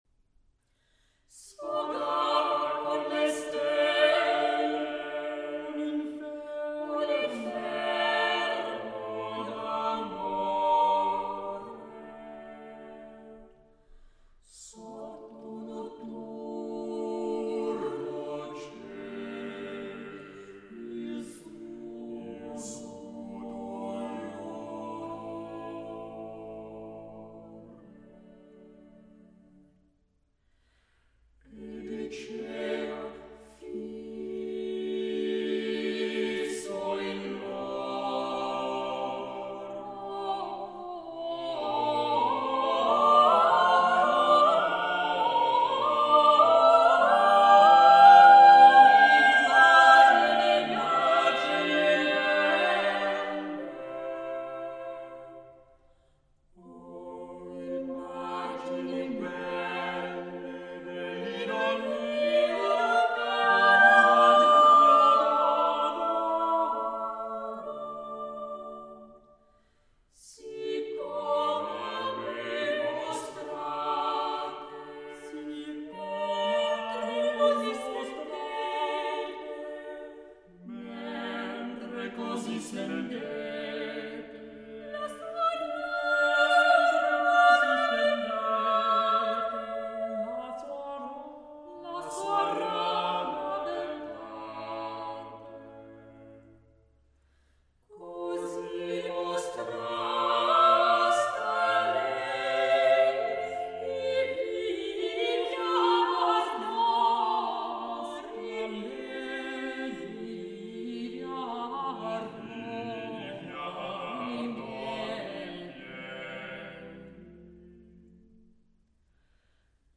04 - Madrigals, Book 4, for 5 voices, SV 75-93- 4.